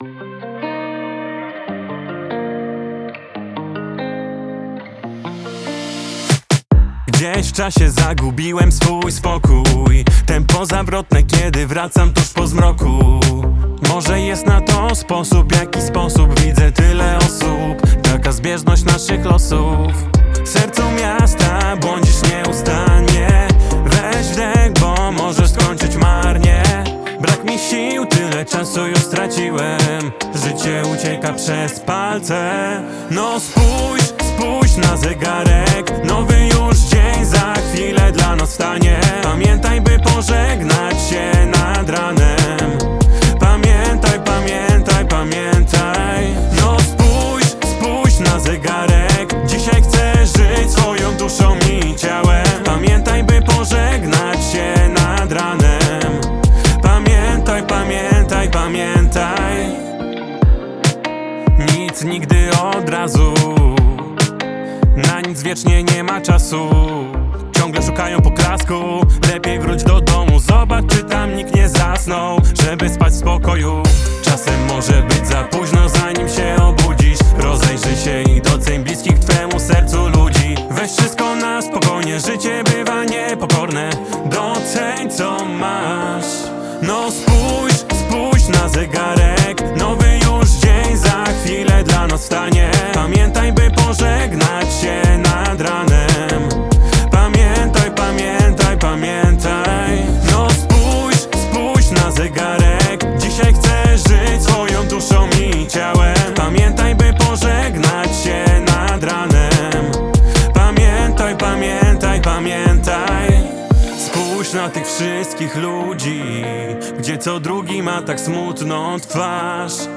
współczesny pop radiowy rytmiczny